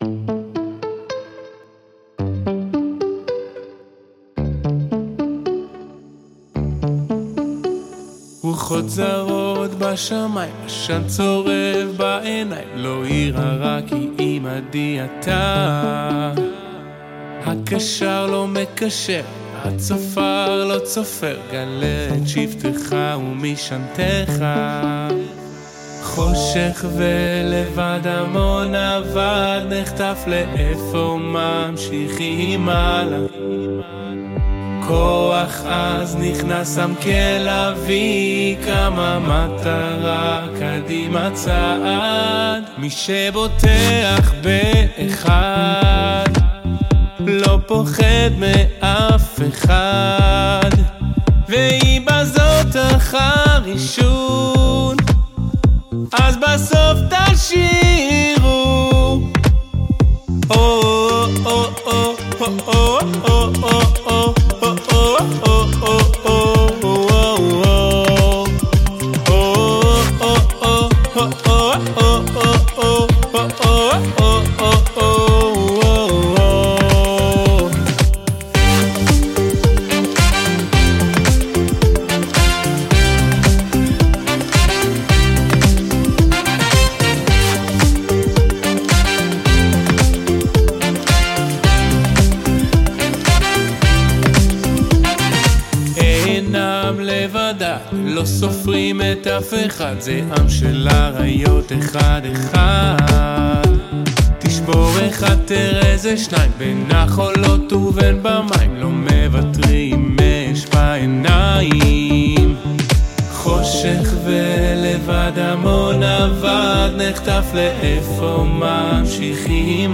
שיר נוגע, מחזק ומלא תקווה